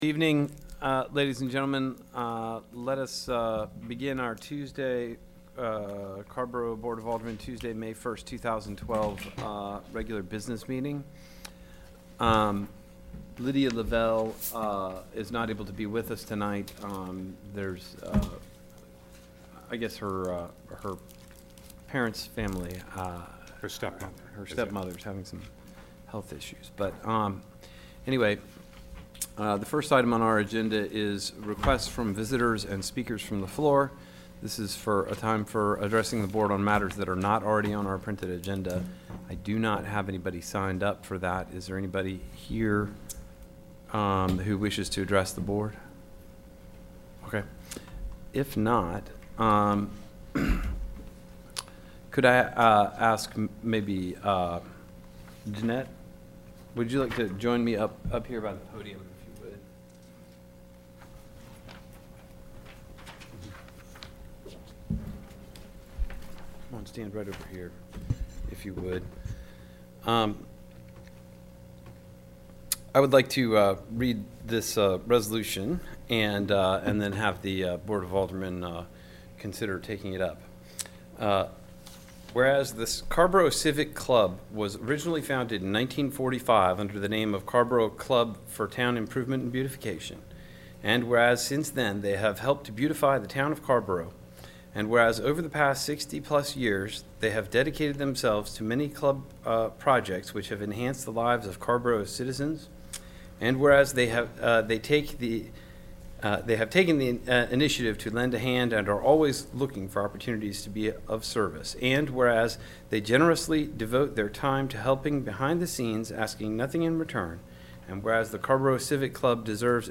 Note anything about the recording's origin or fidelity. AGENDA CARRBORO BOARD OF ALDERMEN Regular Meeting Tuesday, May 1, 2012 7:30 P.M., ORANGE COUNTY HUMAN SERVICES CENTER - 2501 HOMESTEAD ROAD, CHAPEL HILL, NC 27514